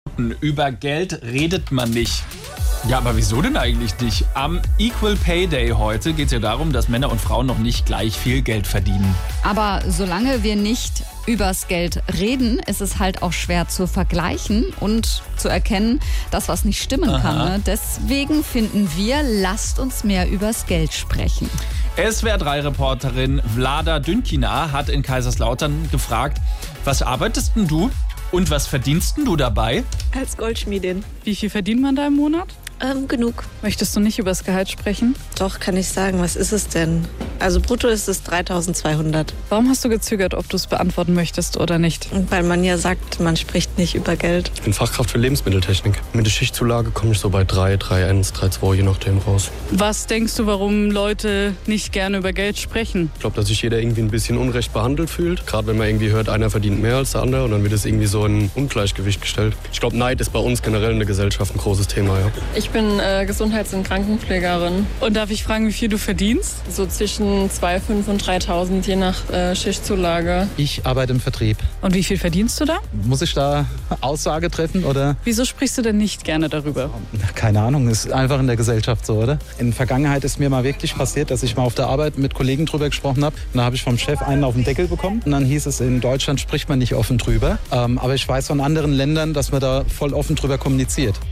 Wir waren einmal unterwegs und haben euch gefragt, was ihr verdient.